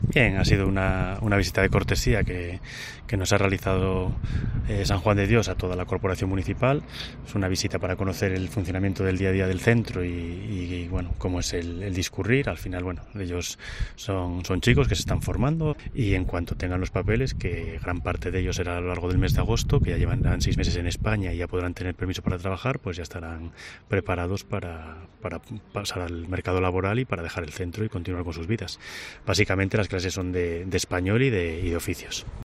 Jorge Pérez, alcalde de Villaquilambre